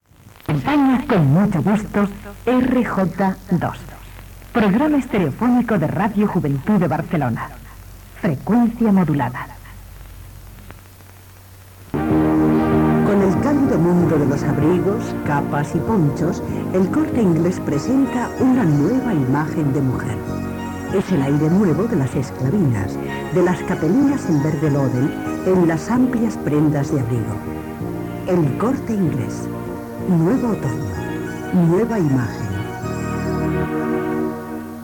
Indicatiu de l'emissora i publicitat
FM